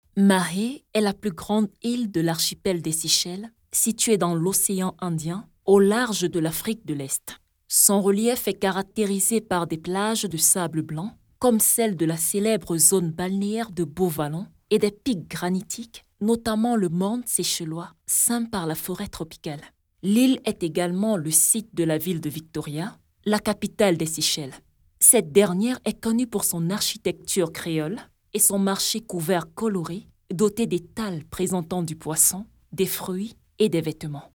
Voix off demo
Je suis en même de faire tout type de voix à l'instar de celle d'une gamine, à celle d'une personne du personne du 3ème Age.
- Basse
Voix off pour Commercial, E-learning, documentaire, Narration Audio